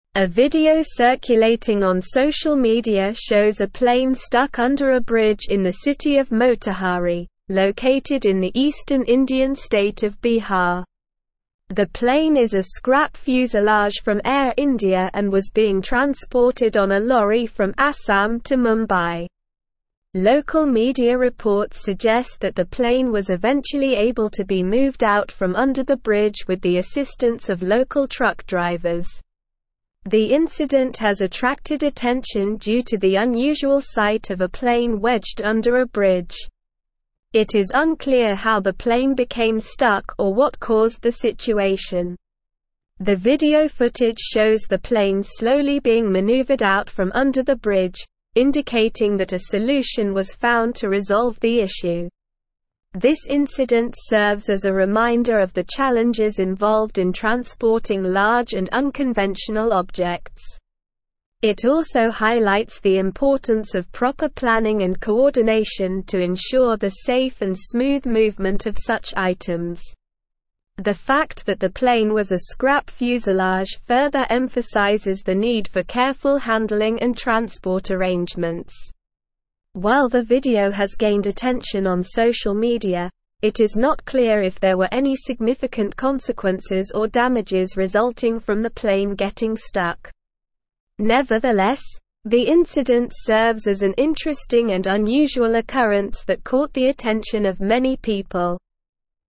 Slow
English-Newsroom-Advanced-SLOW-Reading-Plane-gets-stuck-under-bridge-in-India.mp3